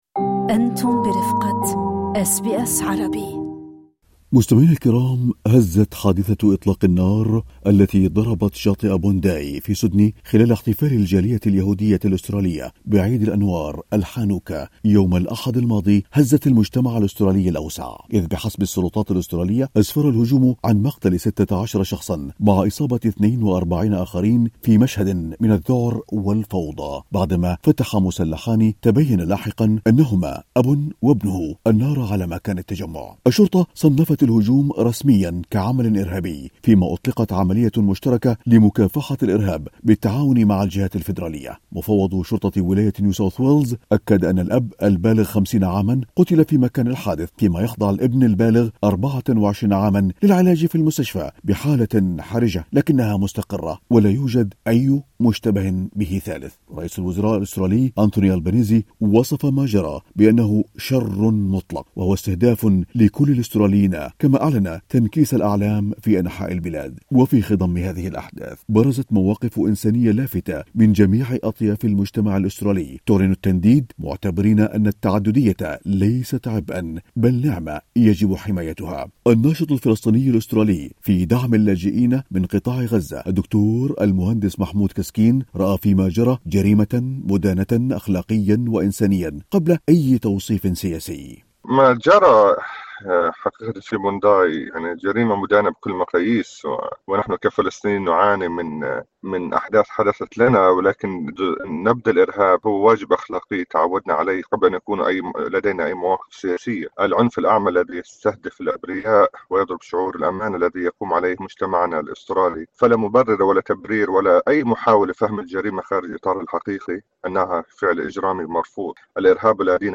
في حديثهم مع أس بي أس عربي